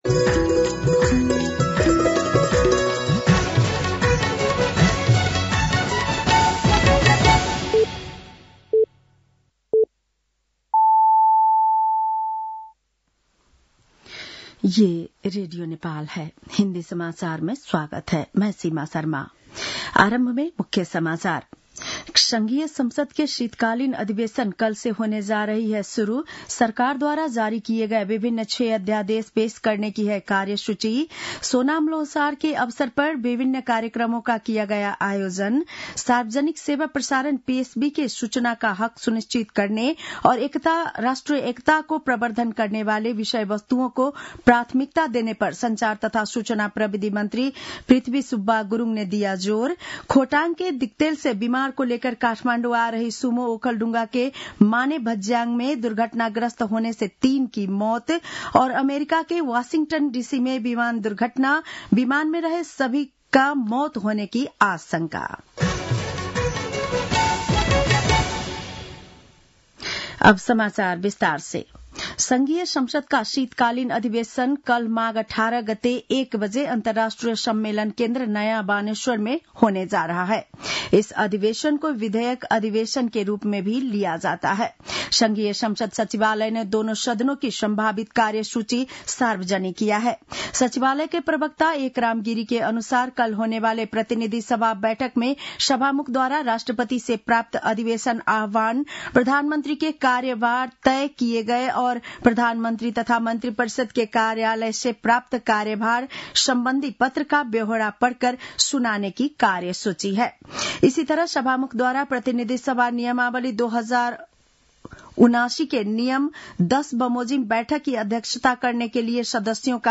An online outlet of Nepal's national radio broadcaster
बेलुकी १० बजेको हिन्दी समाचार : १८ माघ , २०८१